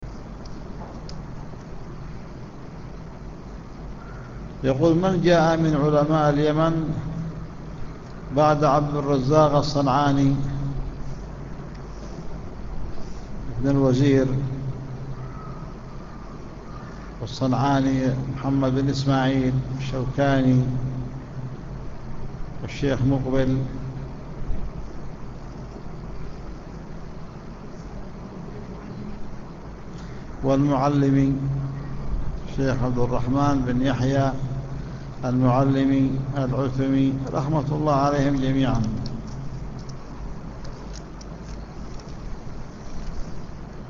وكان هذا في يوم الإثنين في شرحه على "مختصر صحيح البخاري" 17 ذي القعدة 1431هــ